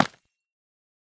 Minecraft Version Minecraft Version 1.21.5 Latest Release | Latest Snapshot 1.21.5 / assets / minecraft / sounds / block / calcite / break1.ogg Compare With Compare With Latest Release | Latest Snapshot
break1.ogg